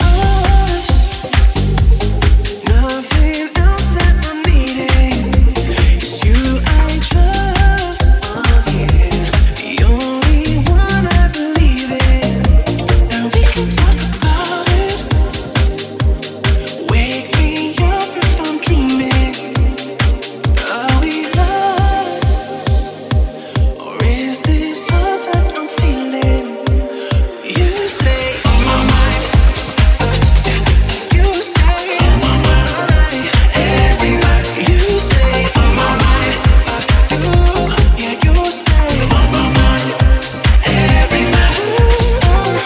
Genere: pop, club, deep, remix